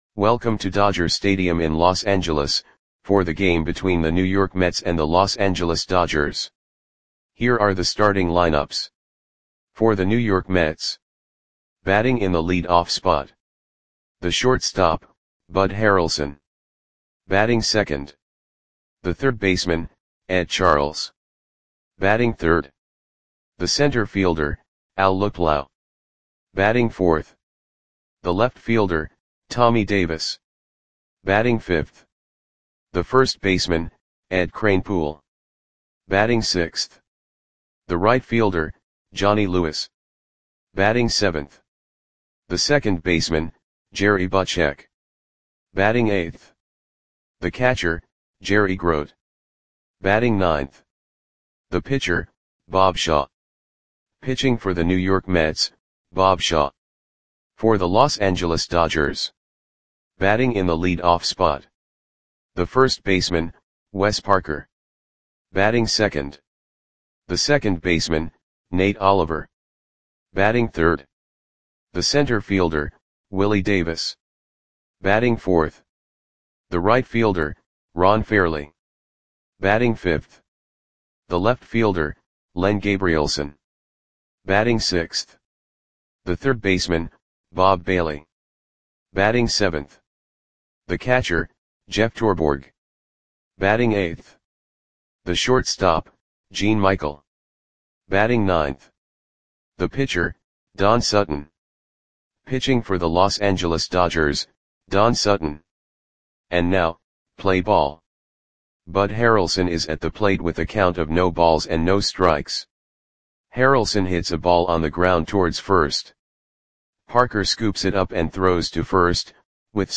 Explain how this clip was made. Mets 2 @ Dodgers 7 Dodger StadiumMay 30, 1967 (No Comments)